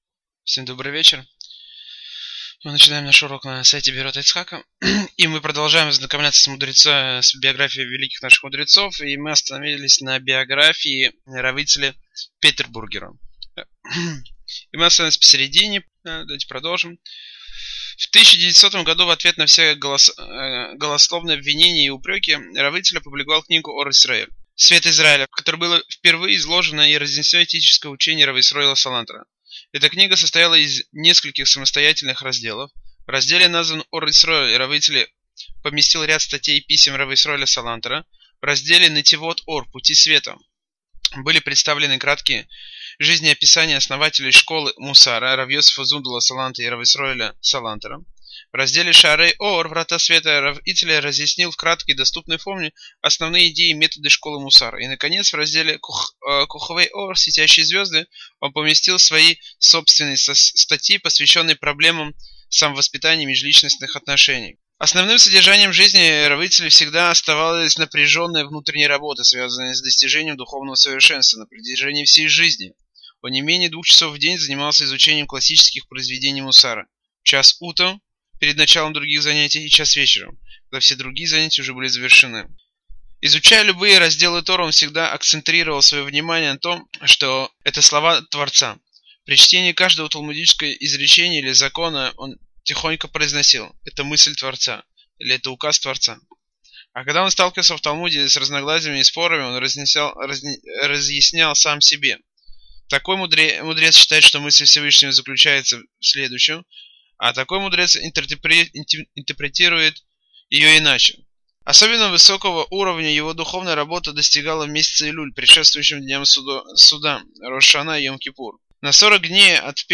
Цикл уроков по Талмуду!